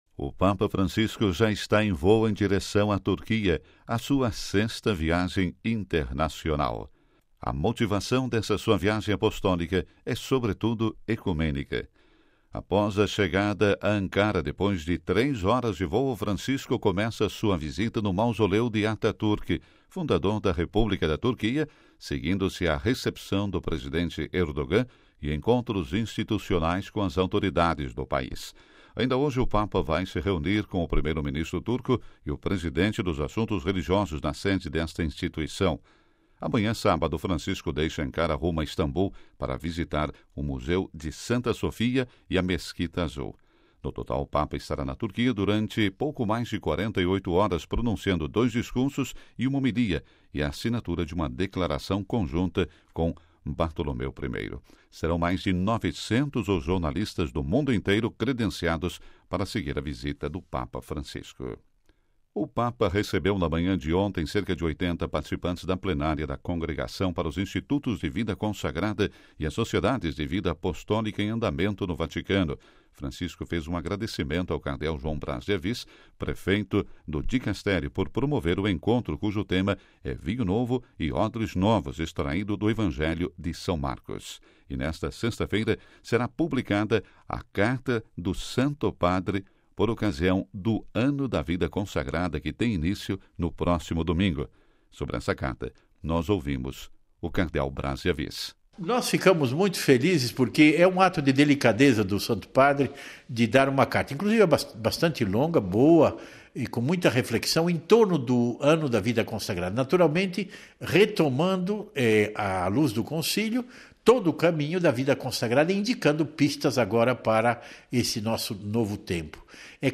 Boletim da Rádio Vaticano - 28/11